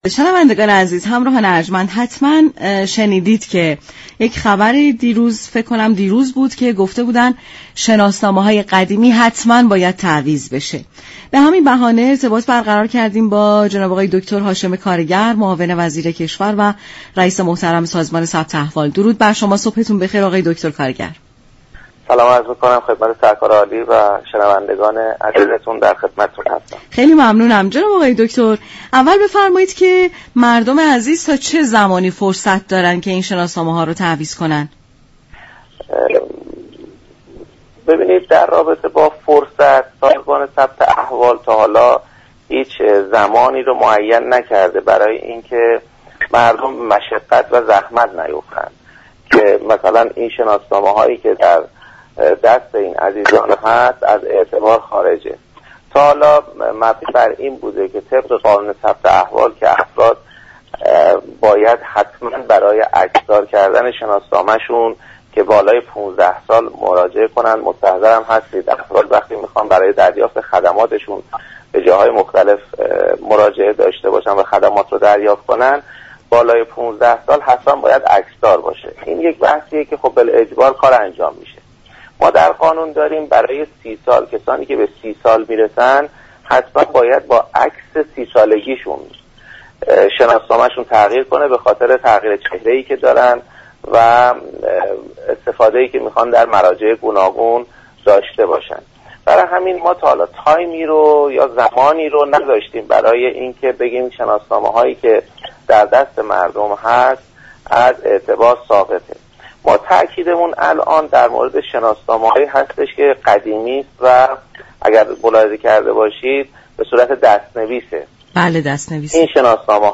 به گزارش شبكه رادیویی ایران، «هاشم كارگر» معاون وزیر كشور و رییس سازمان ثبت احوال، در برنامه «سلام صبح بخیر» رادیو ایران از اجباری شدن تعویض شناسنامه های قدیمی خبر داد و گفت: هر چند سازمان ثبت احوال برای تعویض عكس های شناسنامه فرصت و زمان محدودی را مشخص نكرده است اما به دلیل برخی اشكالات موجود در شناسنامه های قدیمی یا دست نویس، تعویض شناسنامه های قدیمی اجباری شده است.